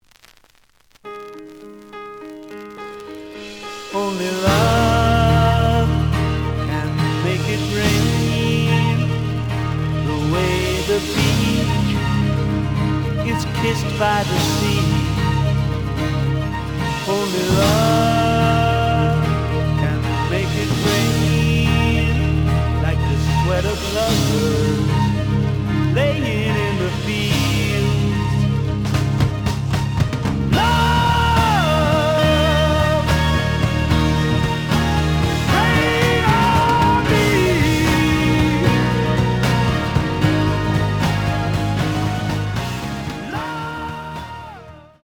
The audio sample is recorded from the actual item.
●Format: 7 inch
●Genre: Rock / Pop
Looks good, but slight noise on both sides.